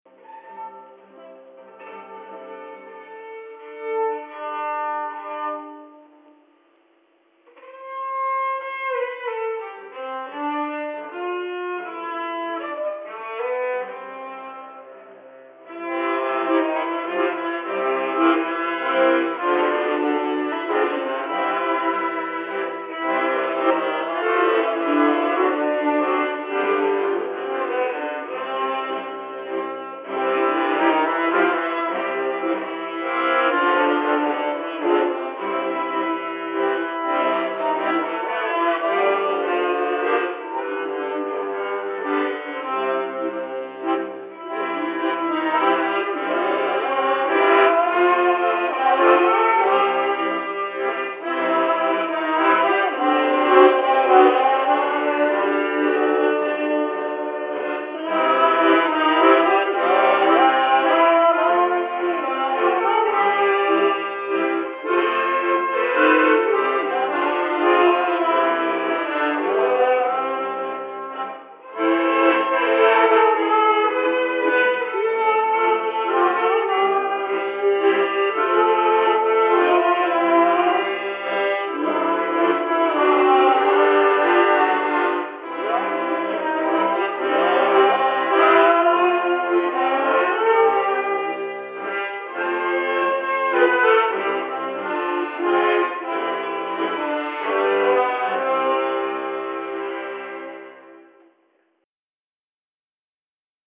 Genre: Deep House, Downtempo.